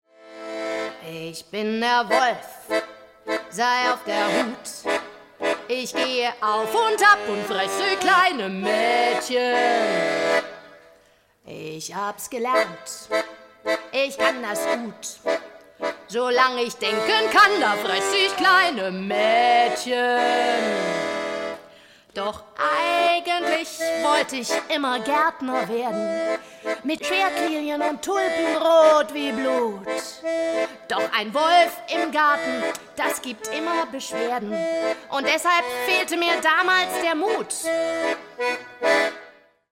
Akkordeon , Gesang , Kinder-Jazz